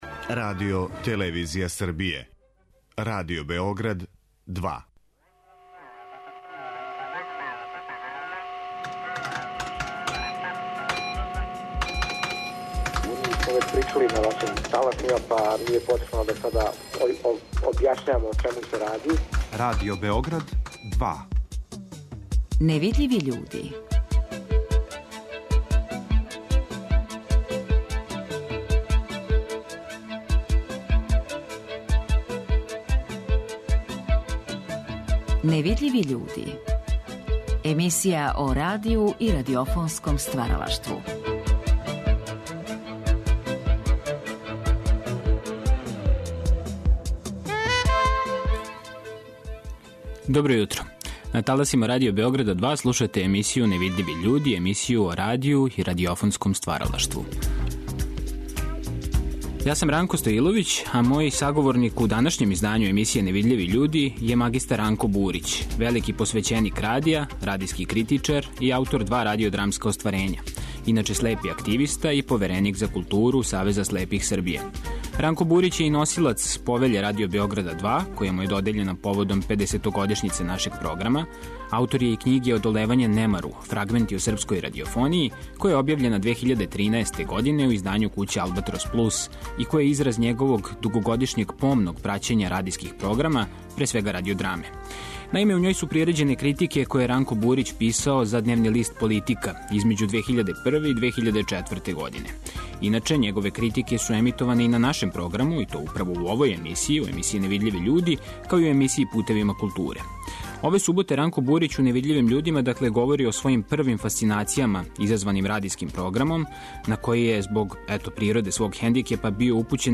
Ове суботе слушаћете разговор